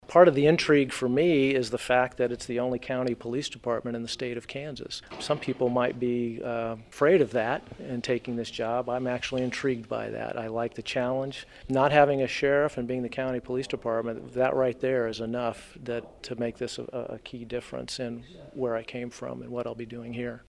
Butler explains what made Riley County an attractive location.